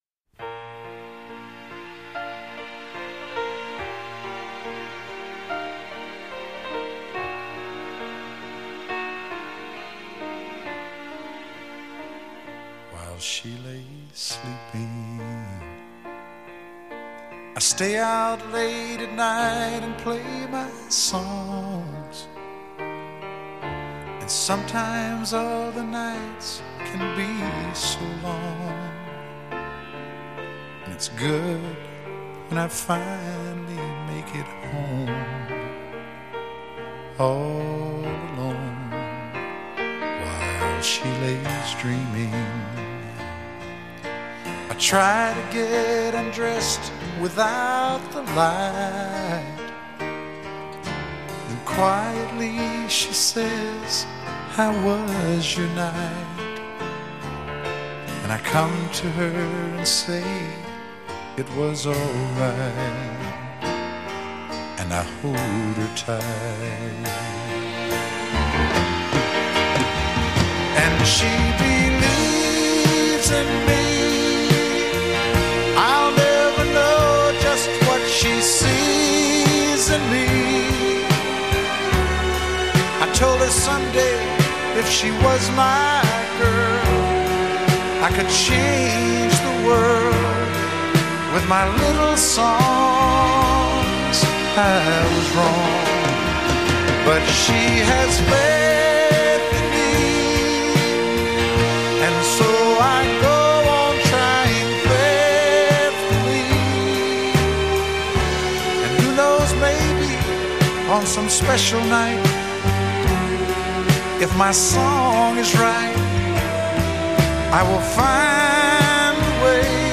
The album mixed country and pop sounds